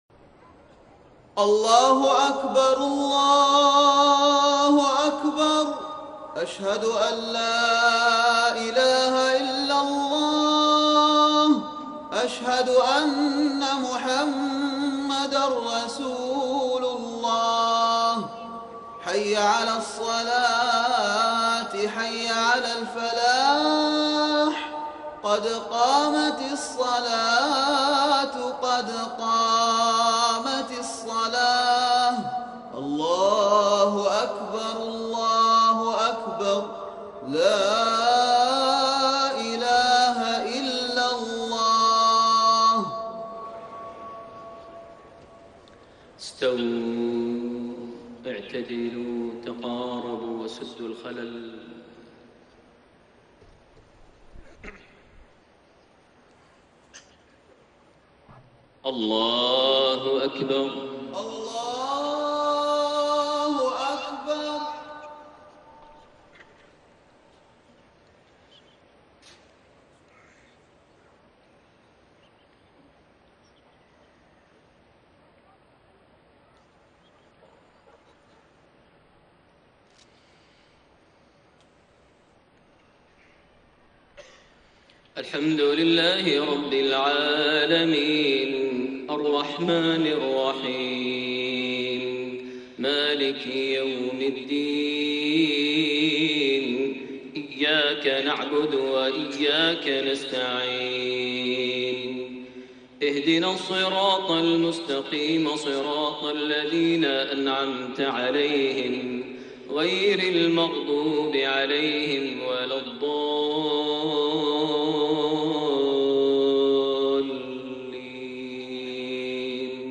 صلاة الفجر 26 رجب 1432هـ | خواتيم سورة الذاريات 24-60 > 1432 هـ > الفروض - تلاوات ماهر المعيقلي